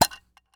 household
Case Plastic Open Metal Rattle